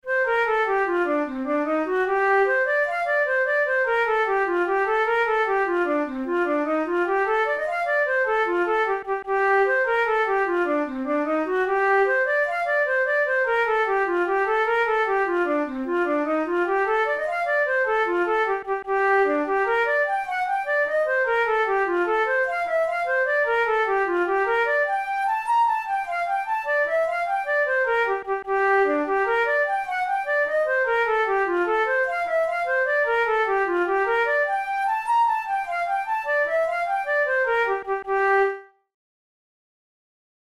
InstrumentationFlute solo
KeyG minor
Time signature6/8
Tempo100 BPM
Jigs, Traditional/Folk
Traditional Irish jig